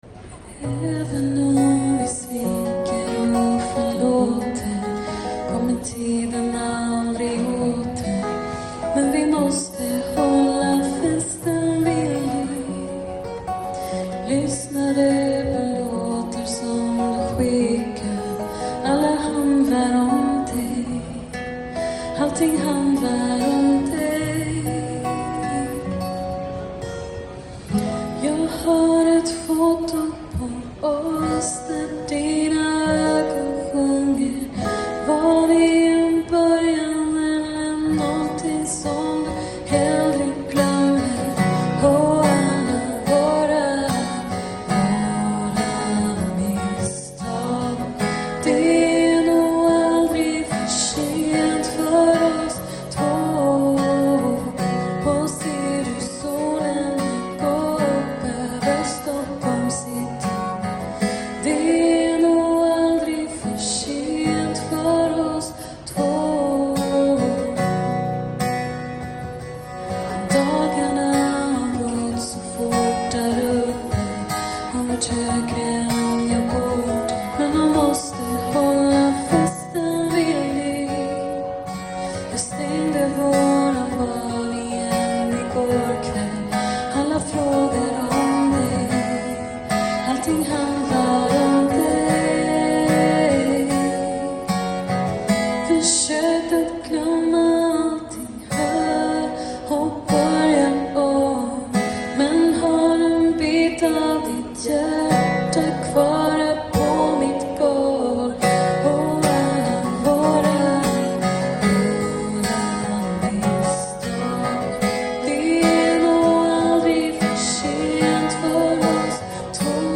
Musikduo